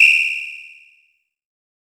Index of /90_sSampleCDs/EdgeSounds - Drum Mashines VOL-1/M1 DRUMS
MWHISTLE2.wav